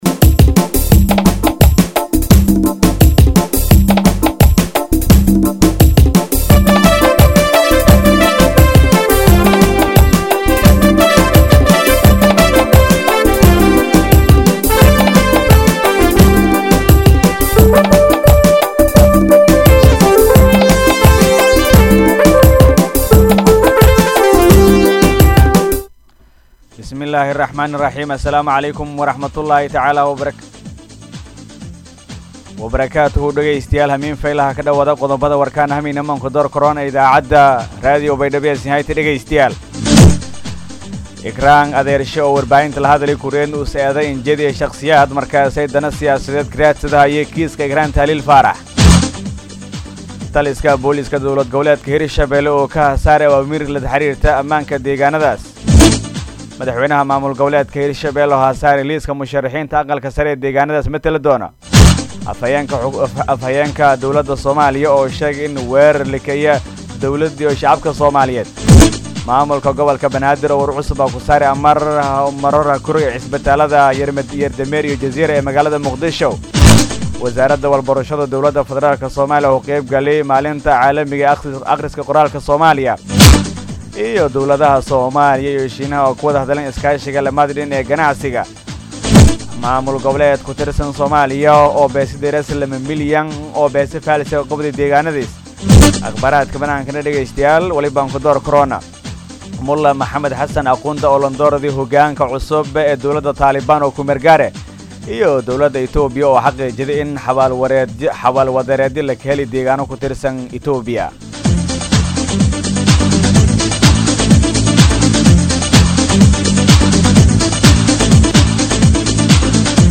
DHAGEYSO:-Warka Habenimo Radio Baidoa 8-9-2021